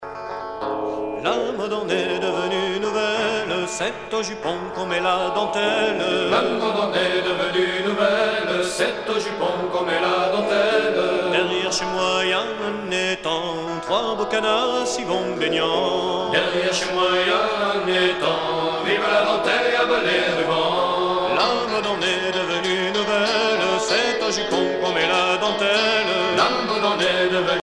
danse : rond de Saint-Vincent
Genre laisse
Pièce musicale éditée